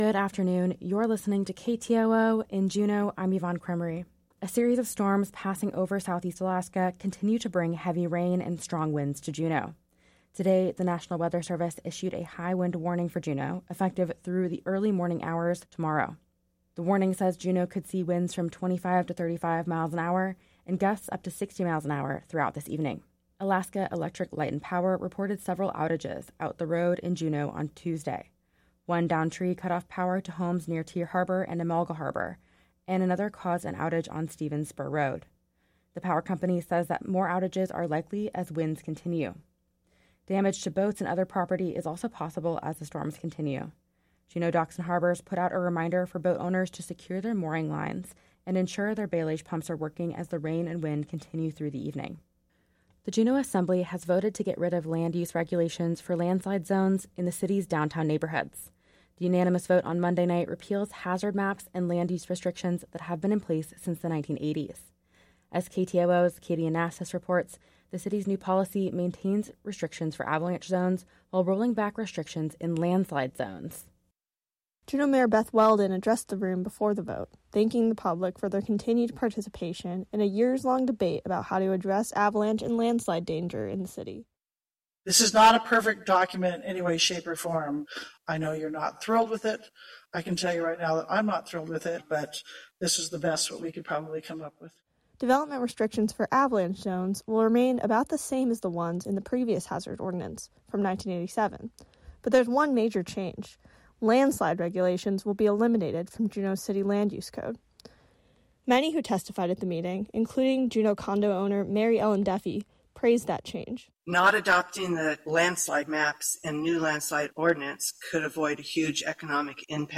Newscast – Tuesday December. 12 2023